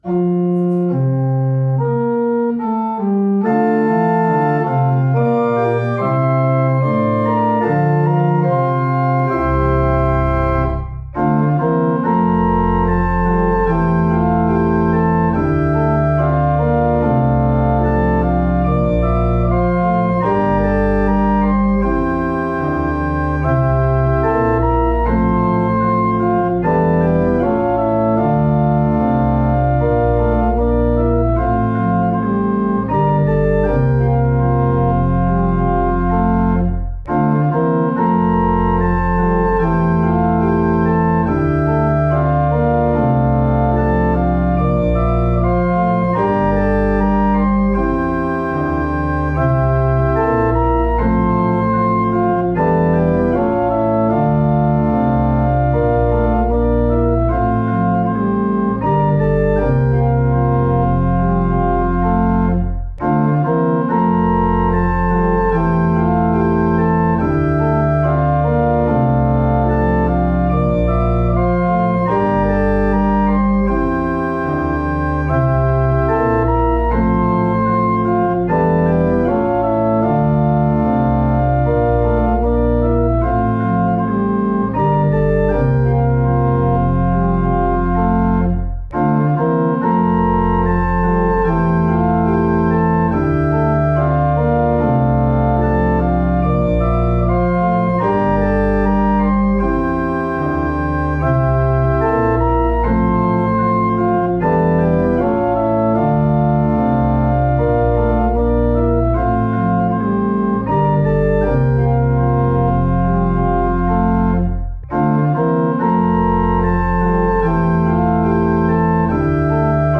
énekszöveg nélkül, csak zene, amire énekelni lehet: